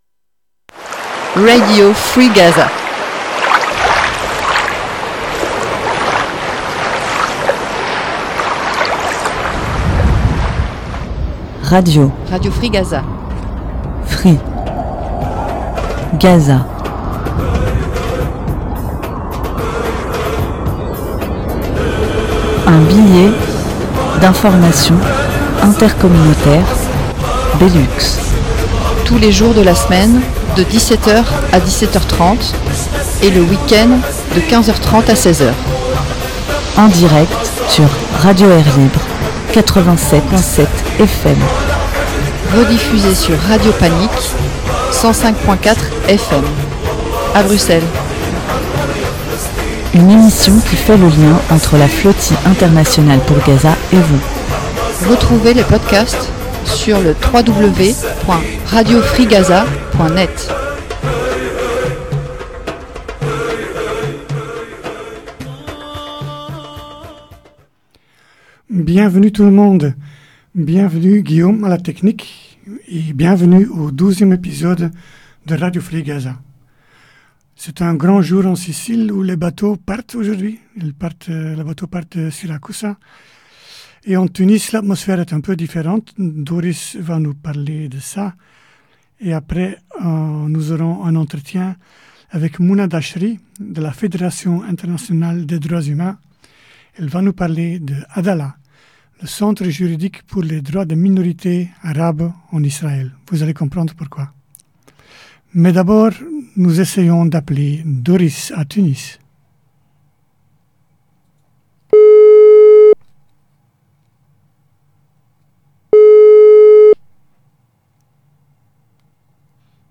Ensuite un entretien